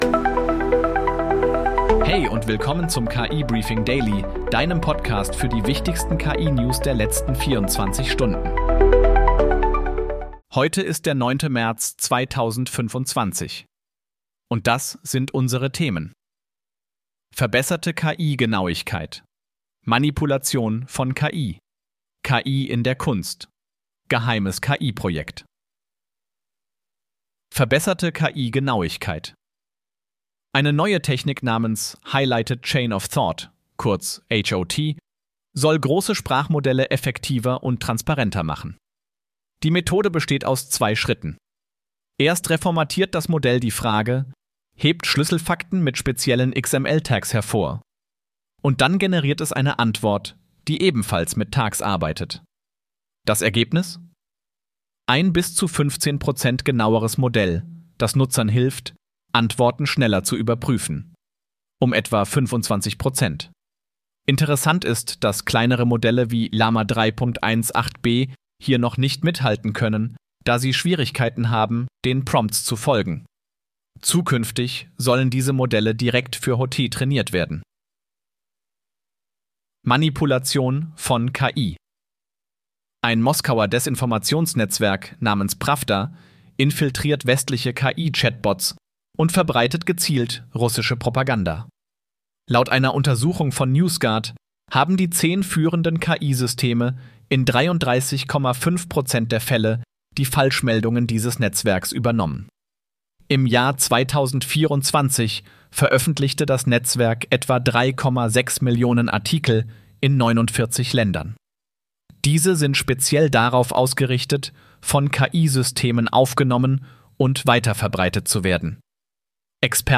Möchtest du selbst einen solchen KI-generierten und 100% automatisierten Podcast zu deinem Thema haben?